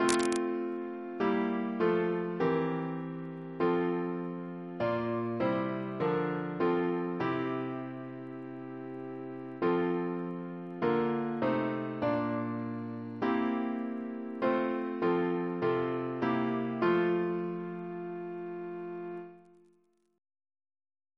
Double chant in F Composer: William Crotch (1775-1847), First Principal of the Royal Academy of Music Reference psalters: OCB: 182; PP/SNCB: 241